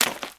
STEPS Swamp, Walk 29.wav